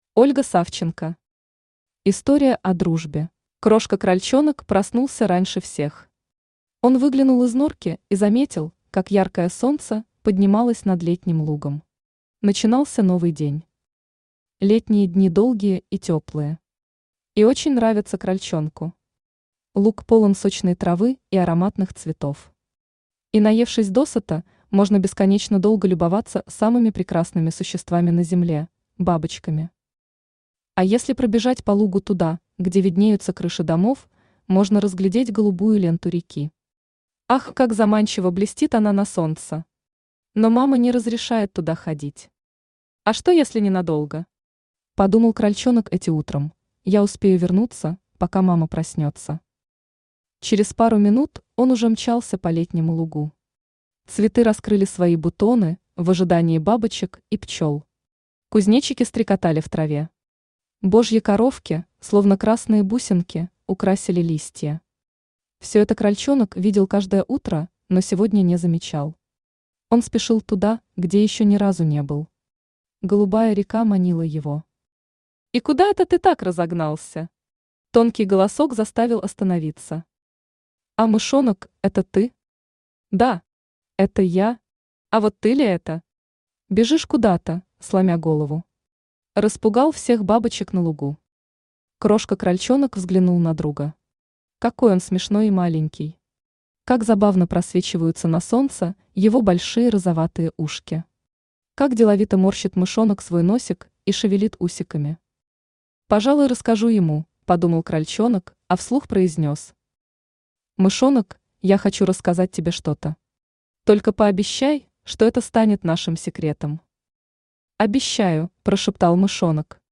Аудиокнига История о дружбе | Библиотека аудиокниг
Aудиокнига История о дружбе Автор Ольга Савченко Читает аудиокнигу Авточтец ЛитРес.